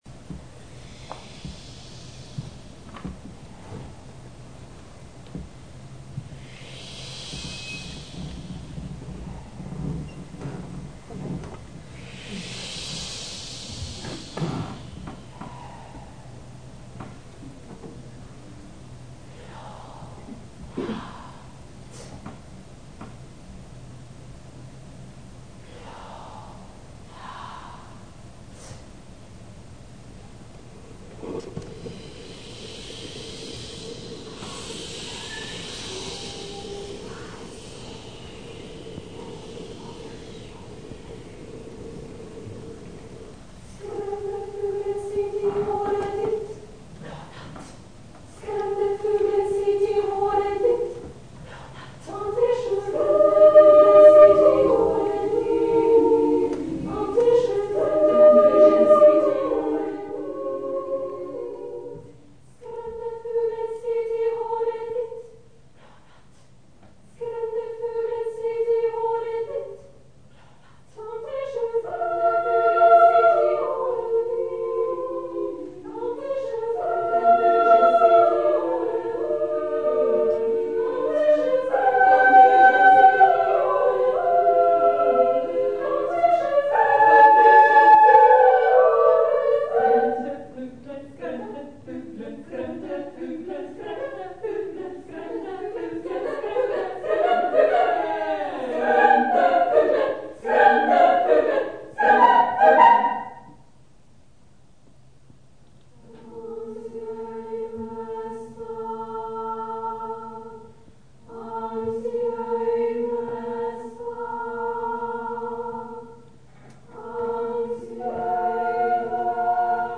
for female choir a cappella (SSSSAAAA)